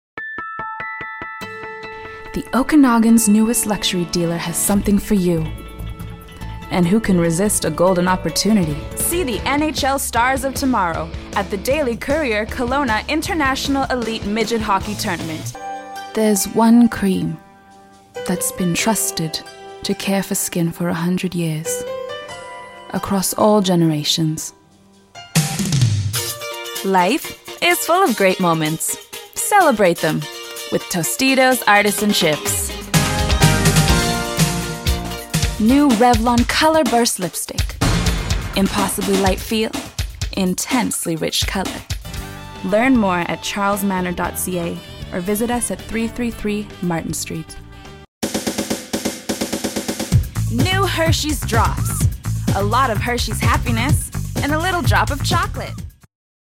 Female Voice Over talent- Years of singing and Acting Experience. Young, Fresh, Mature, Sexy, Wise.
Sprechprobe: Werbung (Muttersprache):